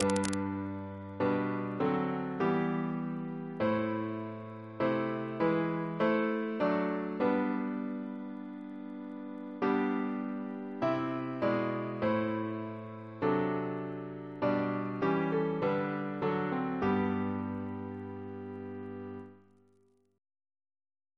Double chant in G Composer: George Cooper (1820-1876) Reference psalters: ACB: 181; RSCM: 35